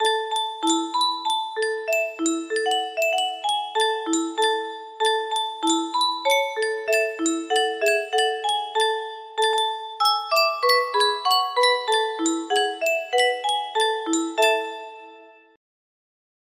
Yunsheng Music Box - Good King Wenceslas Y718 music box melody
Full range 60